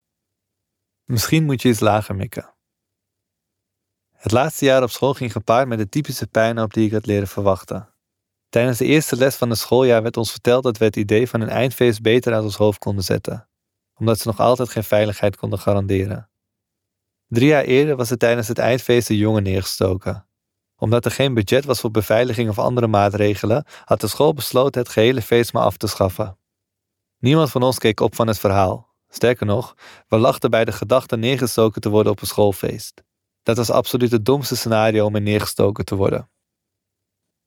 Het audioboek is te beluisteren op Streamingdiensten zoals Storytel en de online bibliotheek en te koop bij luisterboekhandels, zoals Luisterrijk.